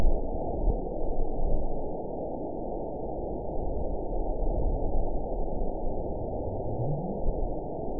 event 911681 date 03/06/22 time 09:34:13 GMT (3 years, 2 months ago) score 9.60 location TSS-AB04 detected by nrw target species NRW annotations +NRW Spectrogram: Frequency (kHz) vs. Time (s) audio not available .wav